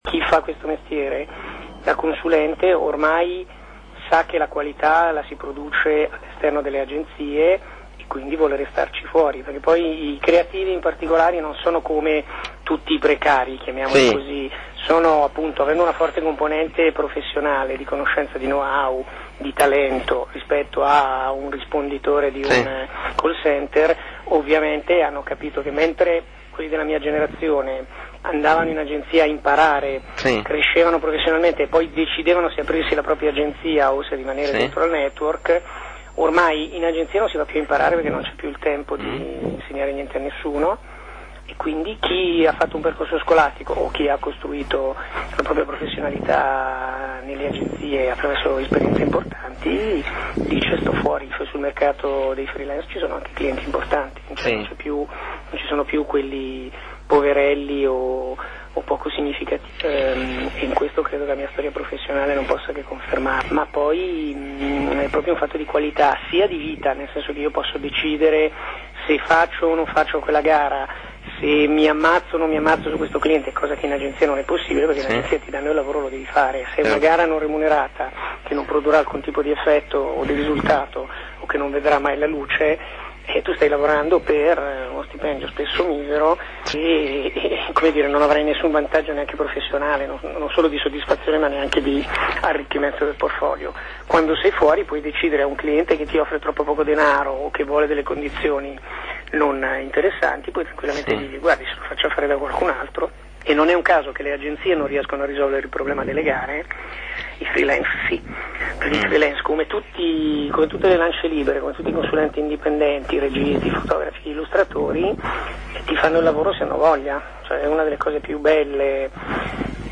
In coda parte dell’intervista telefonica